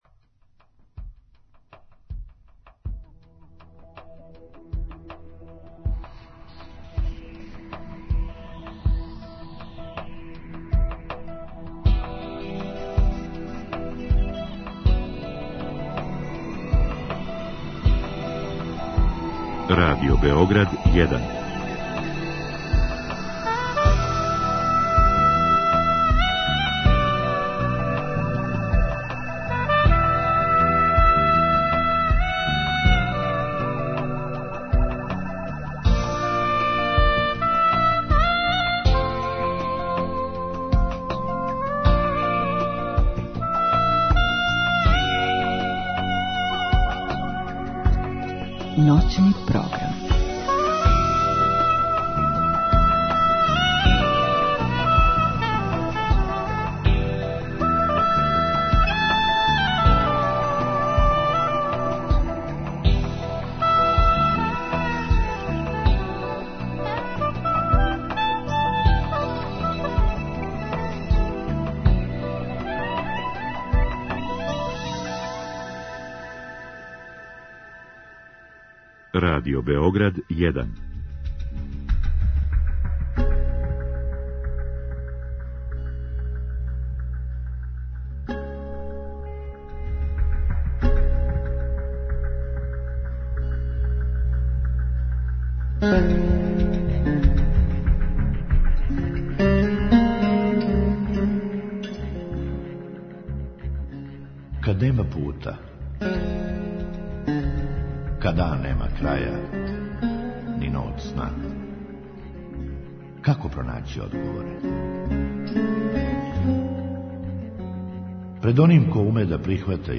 Други сат је резервисан за укључења слушалаца, који гошћи могу поставити питање у програму.